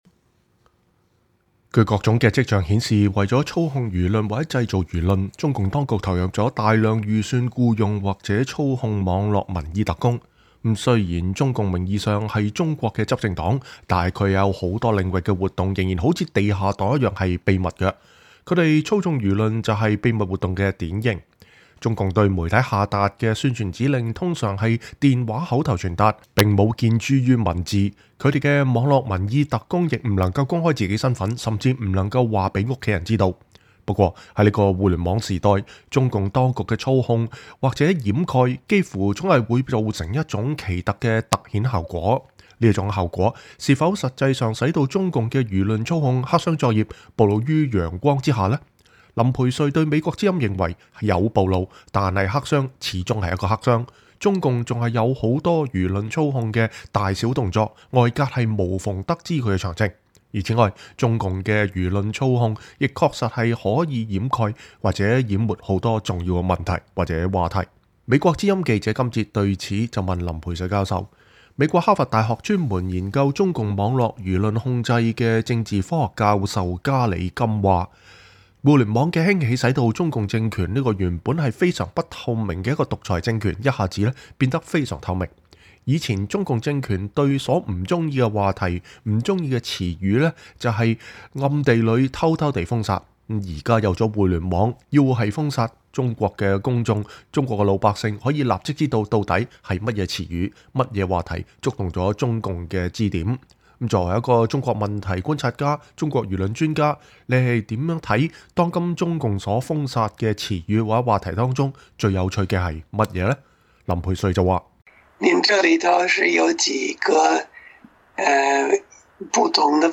專訪林培瑞（4）：談當今中國禁忌詞與話題
以下是美國之音採訪林培瑞教授記錄的第四部分。